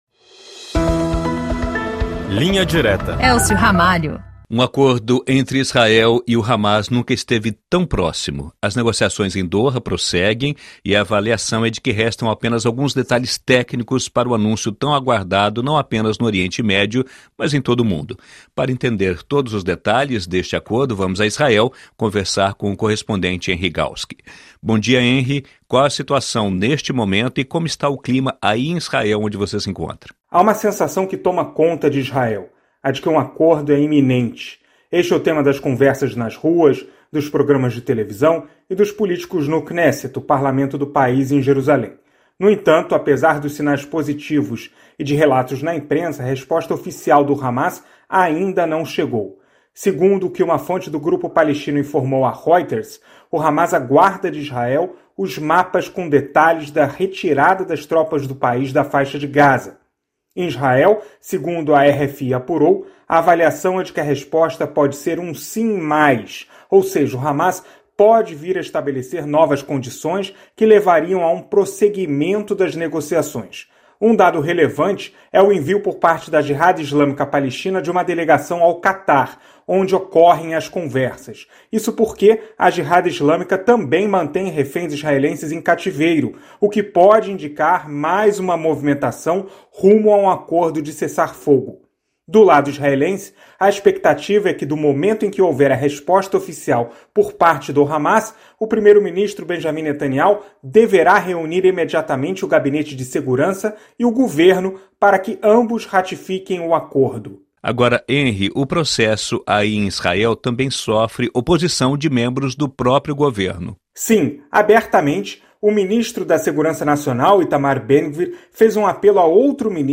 Bate-papo com os correspondentes da RFI Brasil pelo mundo para analisar, com uma abordagem mais profunda, os principais assuntos da atualidade.